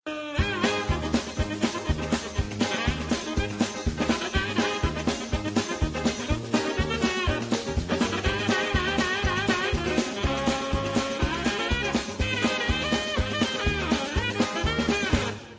Category: Sound FX   Right: Both Personal and Commercial
Tags: meme sound; meme effects; youtube sound effects;